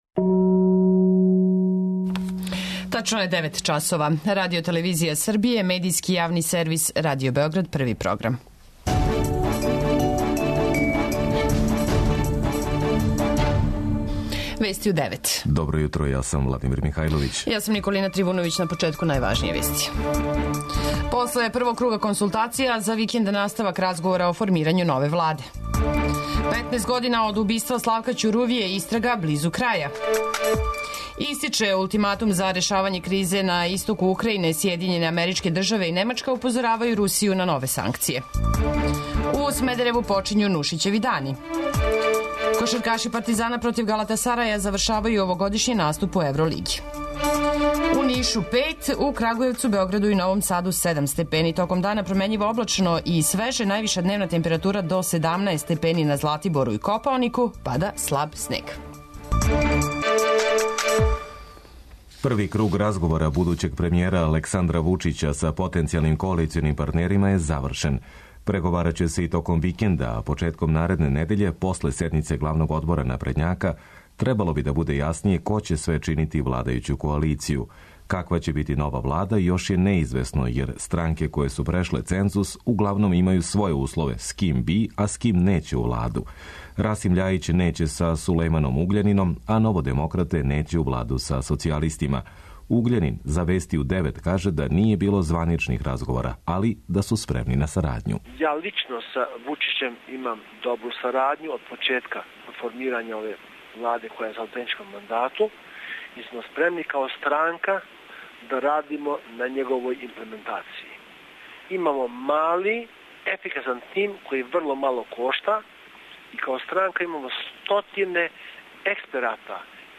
Вести у 9
преузми : 10.02 MB Вести у 9 Autor: разни аутори Преглед најважнијиx информација из земље из света.